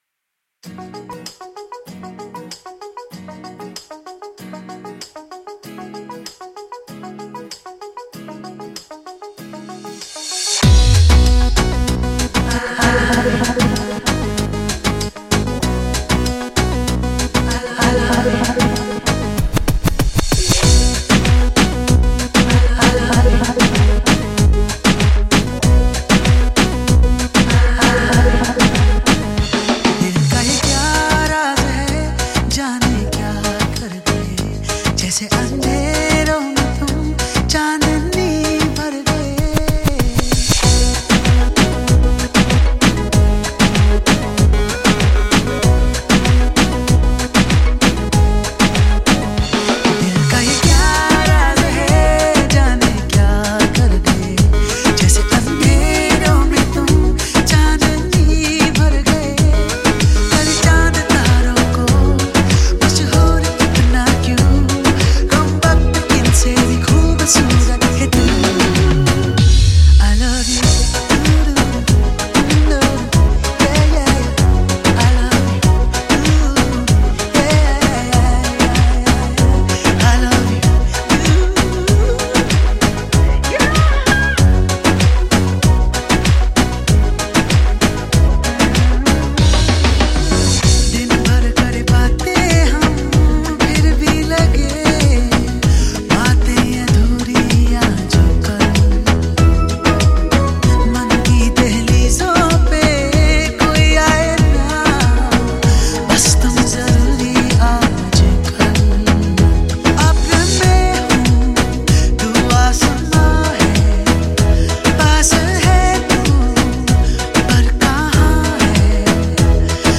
2. Bollywood MP3 Songs